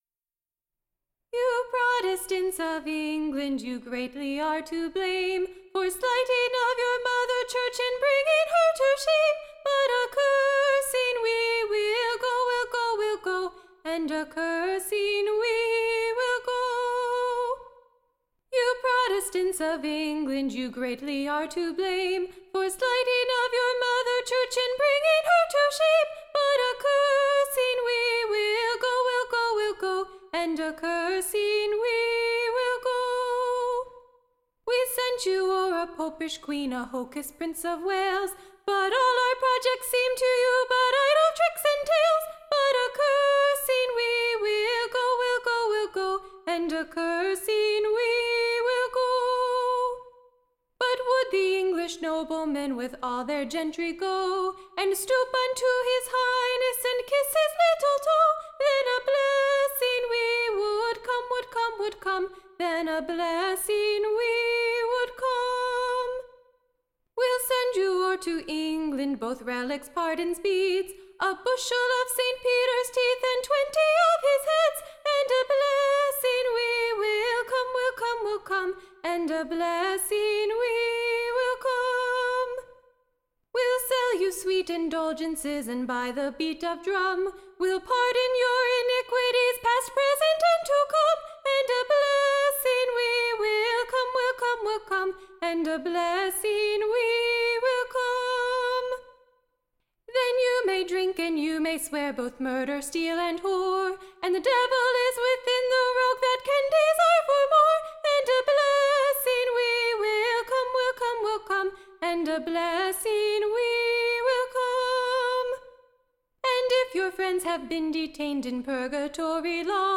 Recording Information Ballad Title NEWS FROM / LONDON-DERRY / In a PACKET of / Advice from Room.